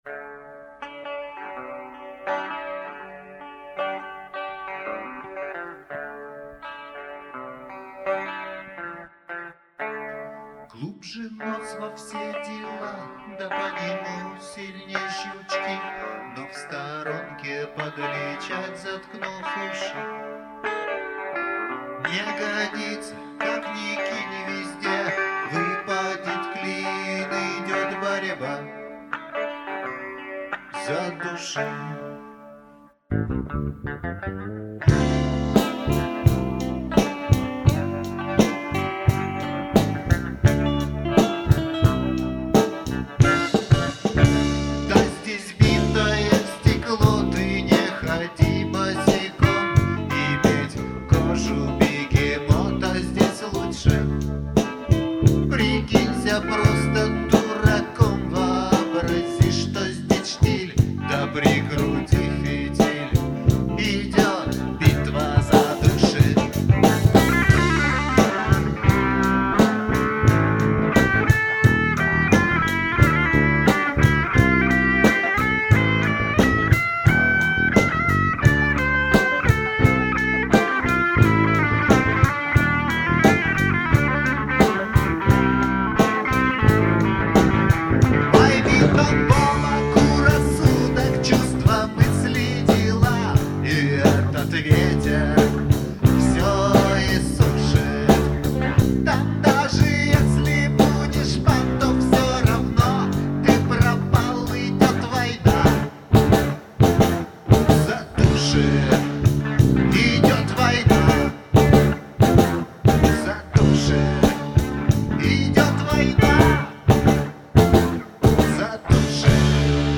Семипалатинск, реставрированные записи - mp3.